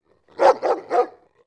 Dog_Large_barking_one_hit_1.wav